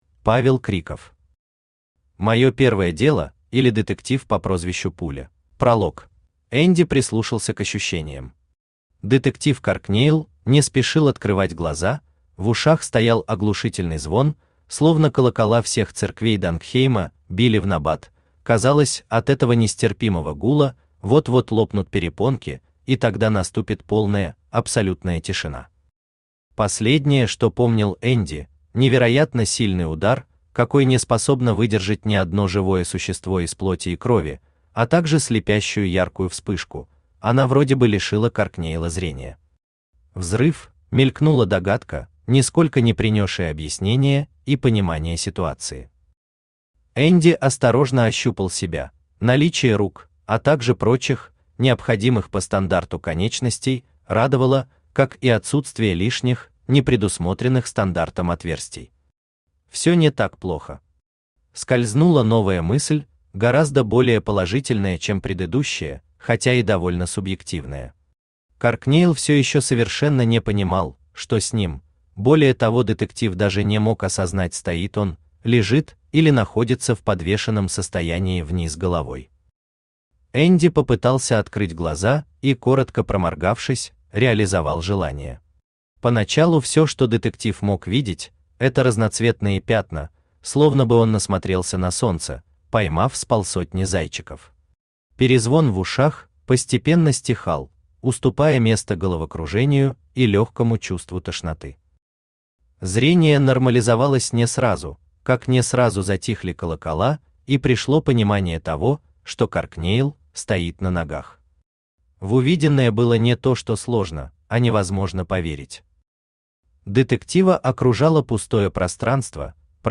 Аудиокнига Моё первое дело, или Детектив по прозвищу Пуля | Библиотека аудиокниг
Aудиокнига Моё первое дело, или Детектив по прозвищу Пуля Автор Павел Криков Читает аудиокнигу Авточтец ЛитРес.